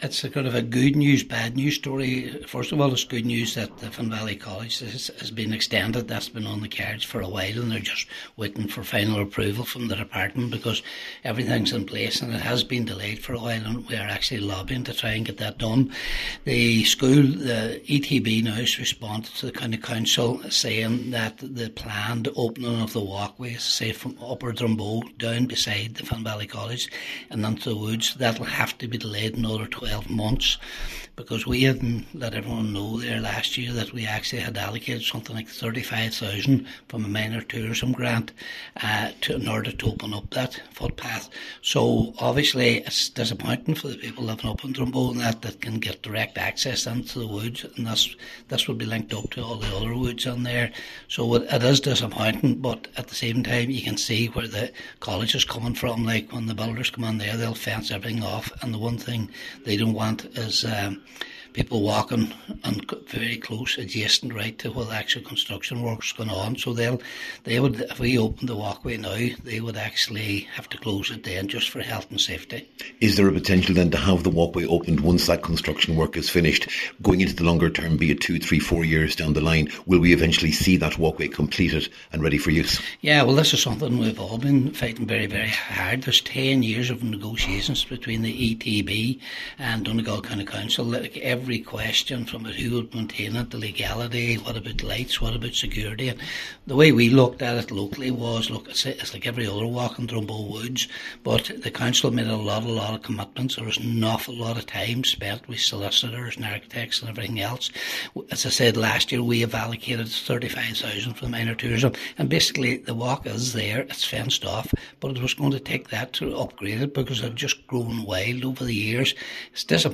Cllr McGowan says the delay is regrettable, but necessary………..